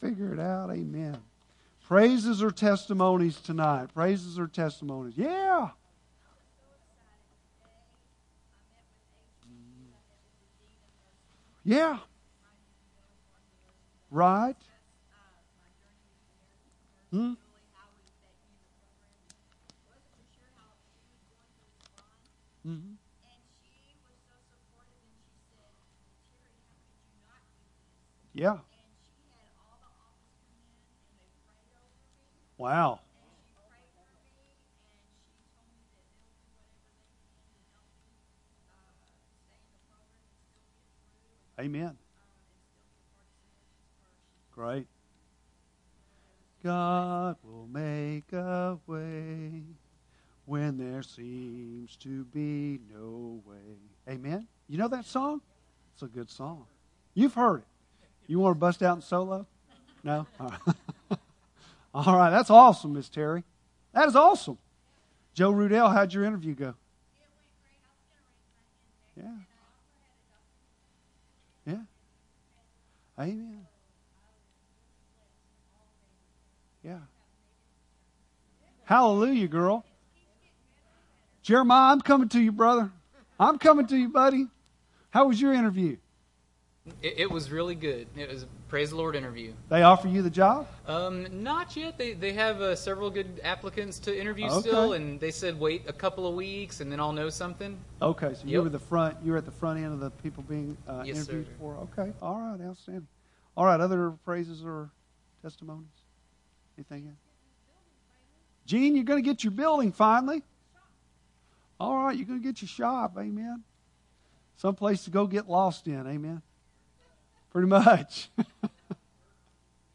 Bible Text: Ruth 2:17-23; 3:1-5 | Preacher